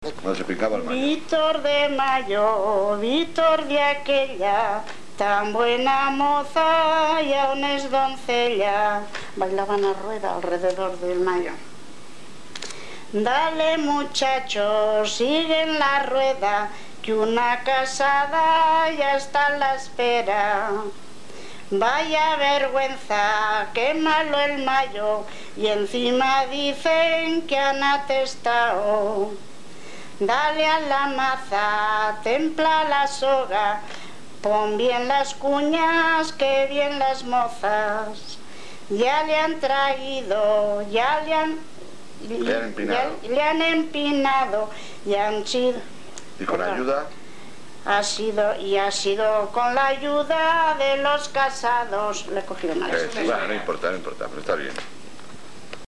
Aldea del Pinar. Jotas y rondas
Escuche los mayos cantados